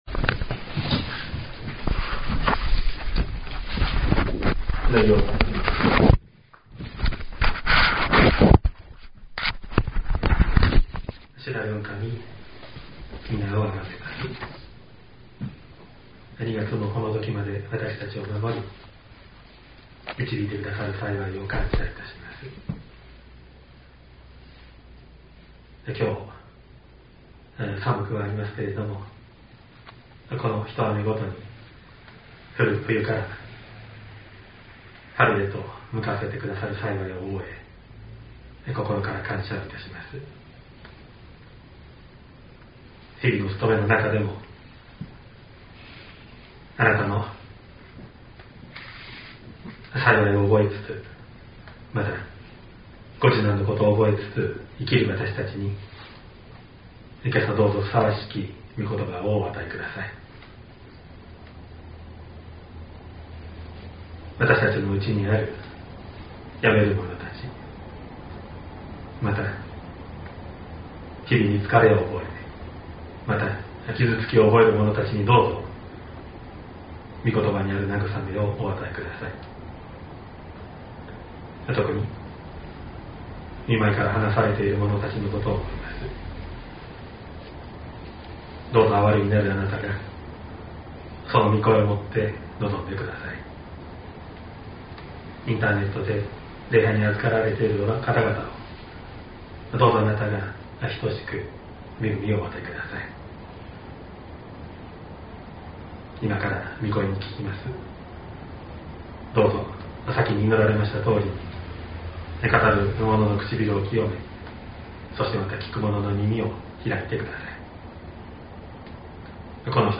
2024年02月25日朝の礼拝「栄光に輝く主イエス」西谷教会
音声ファイル 礼拝説教を録音した音声ファイルを公開しています。